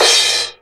Crashes & Cymbals
Crash2.wav